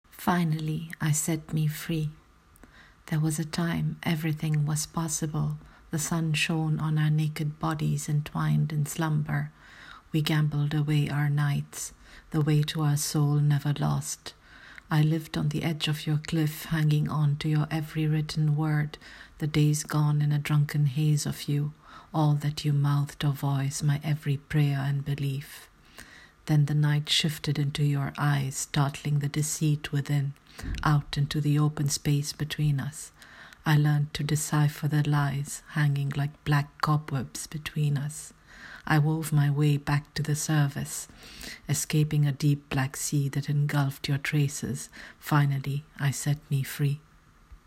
Reading of my poem: